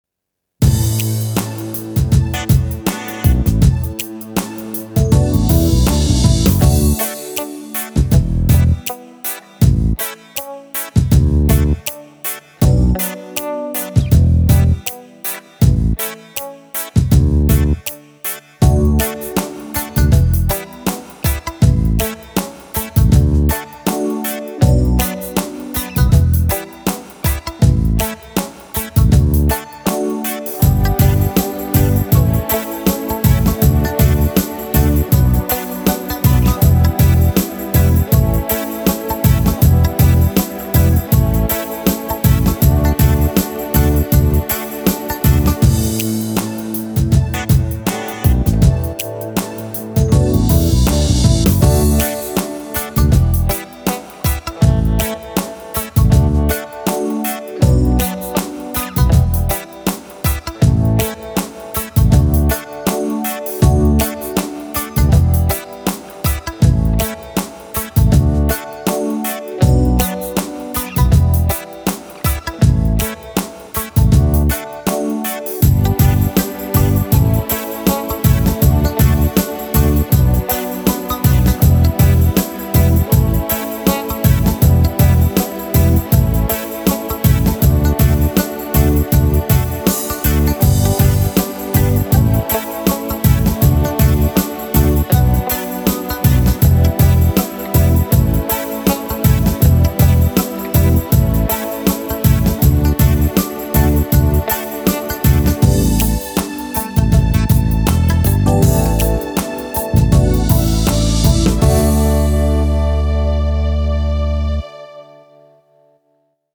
RARE DYR – Singback HER: